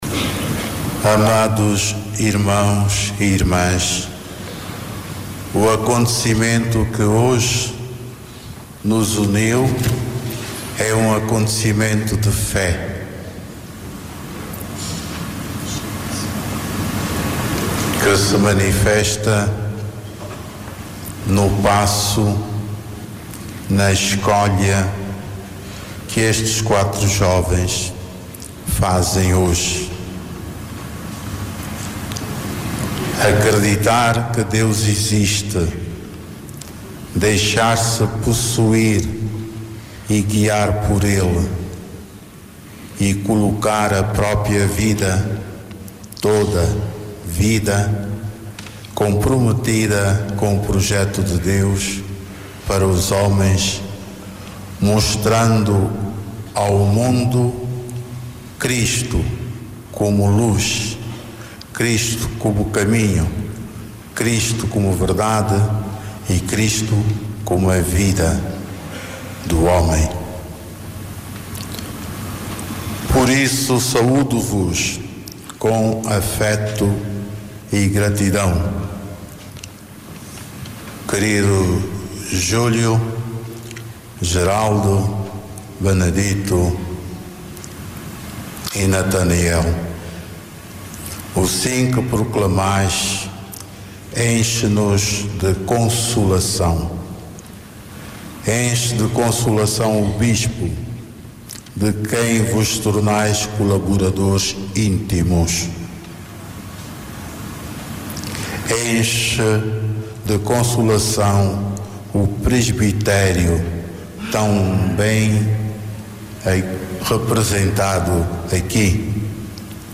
Arquidiocese de Luanda acolheu neste Domingo 4 ordenações diaconais numa missa que teve lugar na sé catedral.
HOMILIA-DOM-FILOMENO-ORDENACAO.mp3